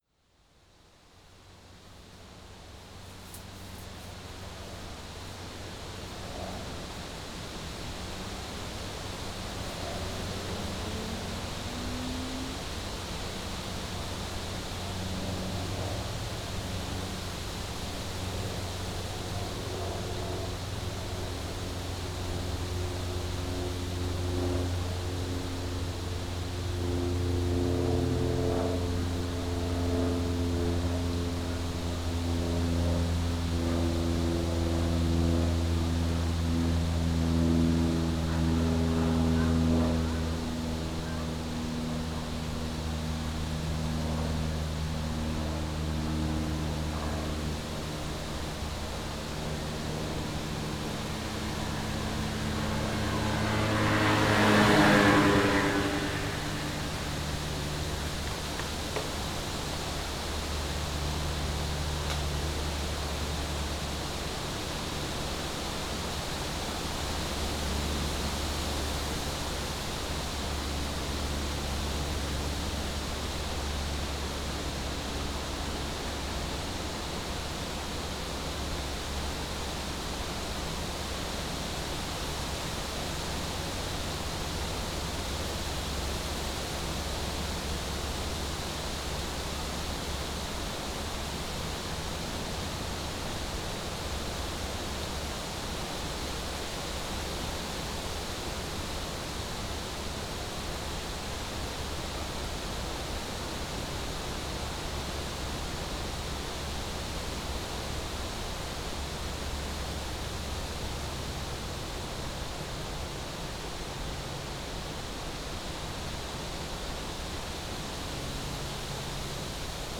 all the sounds you hear right here belong to instant composition #005